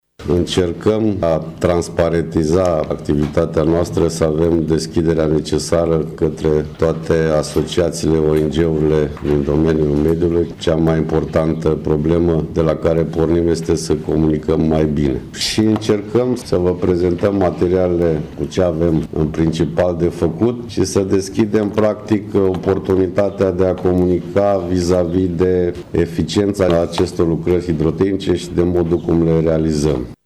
la dezbaterea publică privind programul de investiții și lucrări ale ABA Mureș pe 2016